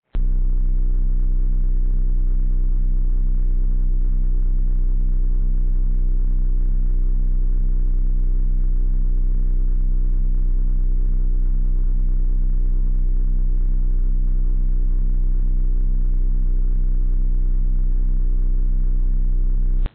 saber_on.wav